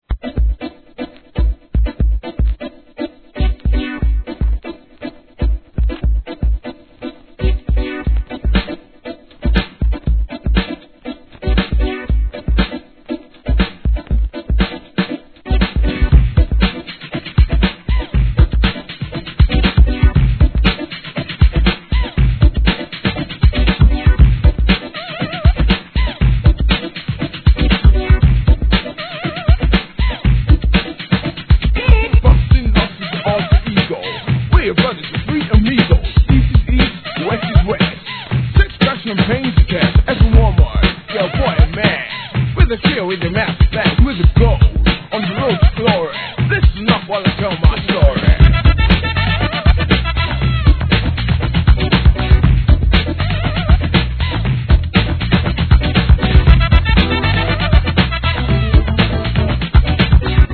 HIP-HOUSE CLASSIC!!